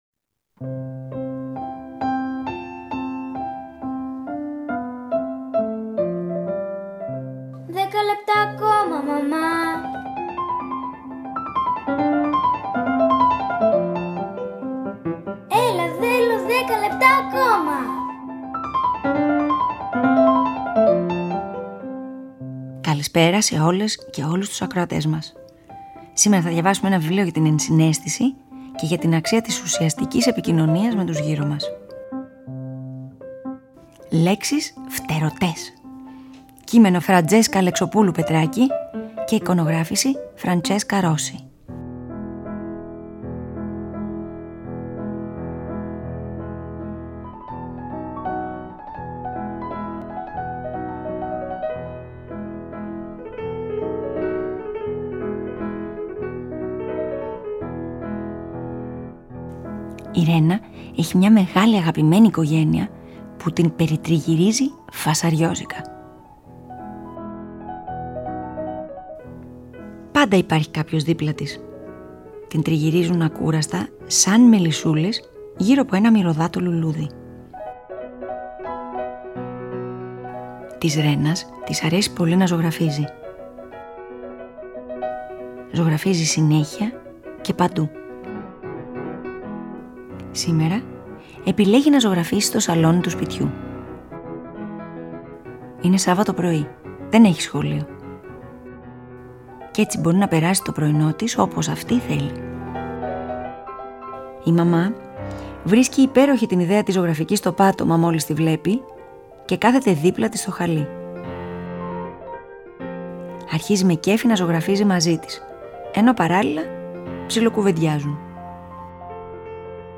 Σήμερα διαβάζουμε ένα βιβλίο για την ενσυναίσθηση￼ και για την αξία της ουσιαστικής επικοινωνίας με τους γύρω μας.